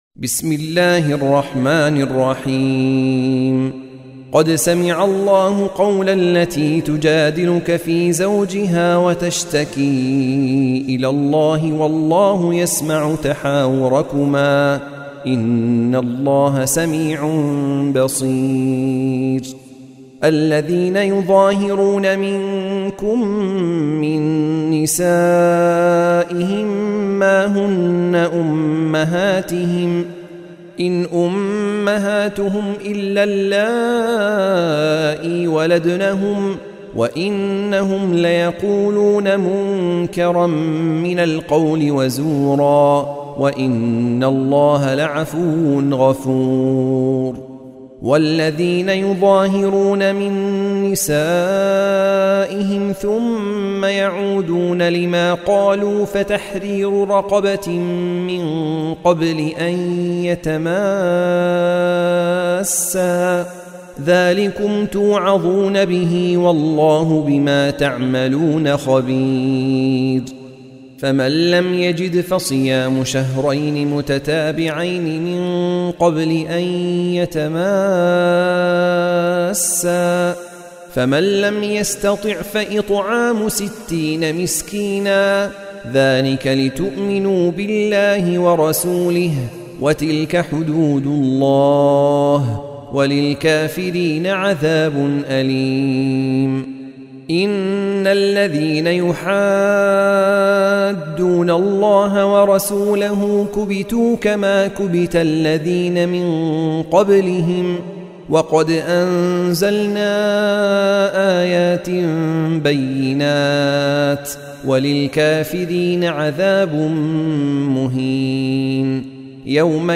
سورة المجادلة | القارئ